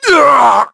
Shakmeh-Vox_Damage_kr_06.wav